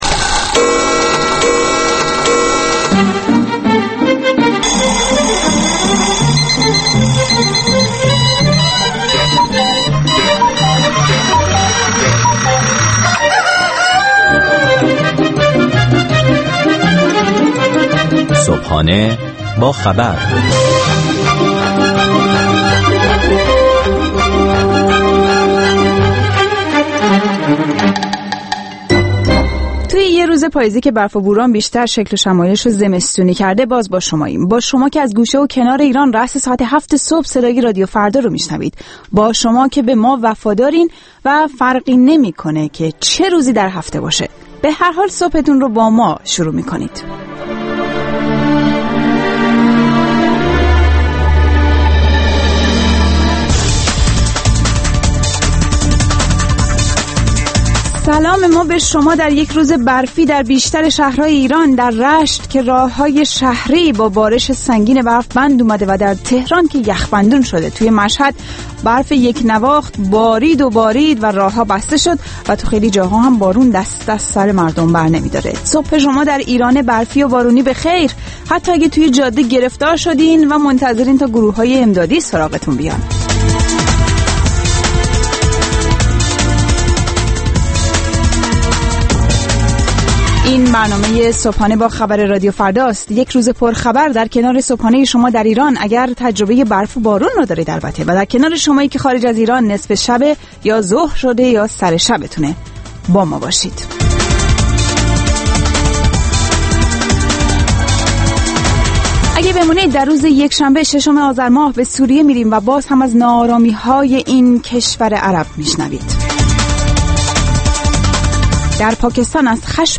بامداد خود را با مجله «صبحانه با خبر» راديو فردا آغاز کنيد. گزارشگران راديو فردا از سراسر جهان، با تازه‌ترين خبرها و گزارش‌ها، مجله‌ای رنگارنگ را برای شما تدارک می‌بينند.